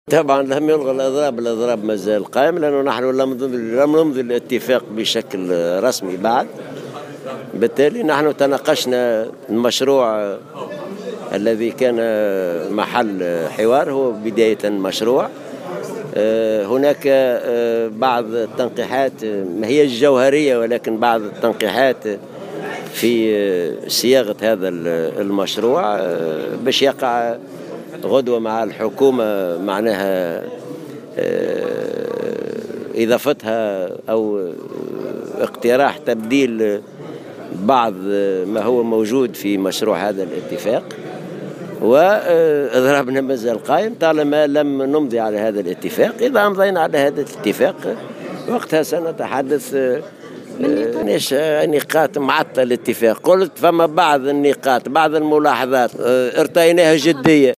قال الأمين العام للاتحاد العام التونسي للشغل حسين العباسي، في تصريح لمراسلة الجوهرة أف أم، عقب اجتماع الهيئة الإدارية للاتحاد، فجر اليوم الأربعاء، إن الإضراب العام في الوظيفة العمومية لا يزال قائما في ظل عدم إمضاء الاتفاق بشكل رسمي مع الحكومة حول الزيادة في الأجور.